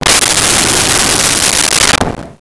Wind Howl 01
Wind Howl 01.wav